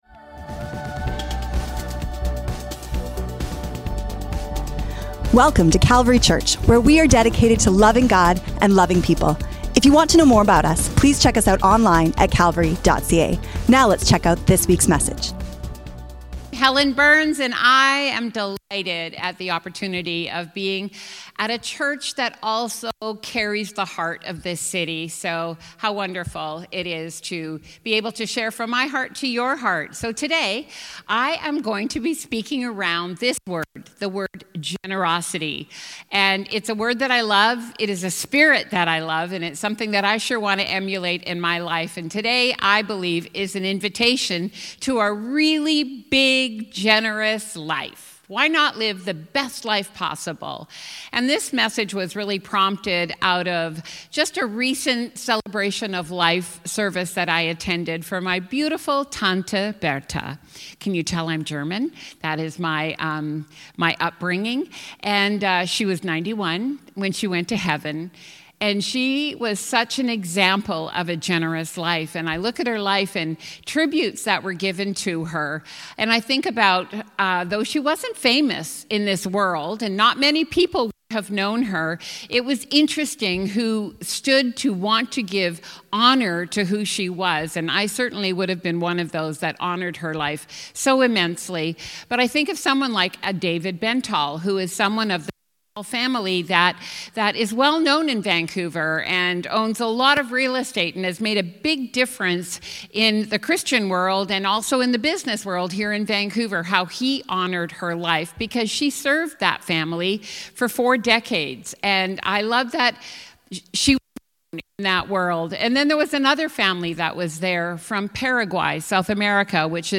We’re thrilled to welcome passionate guest speakers from across Canada, North America and beyond, all united in one mission: sharing the gospel with the world.